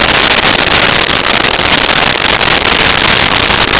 Sfx Amb Wfalls Near Loop
sfx_amb_wfalls_near_loop.wav